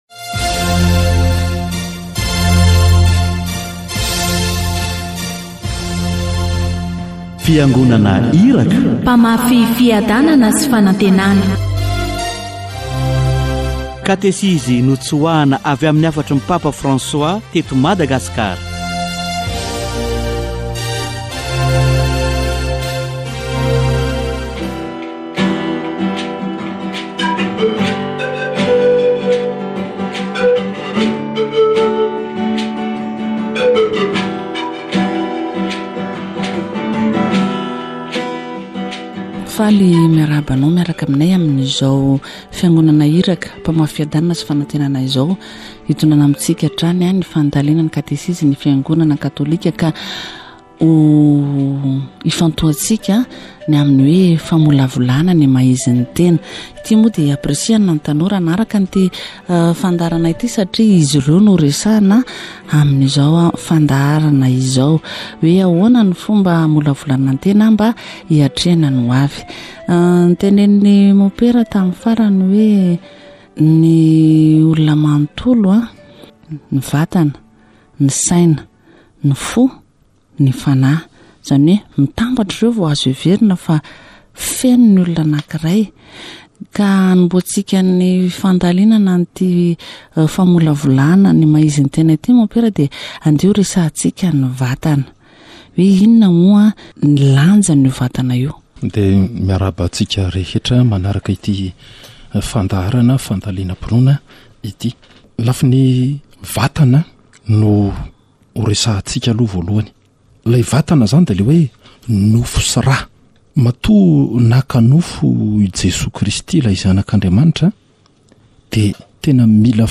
Catechesis on the assertiveness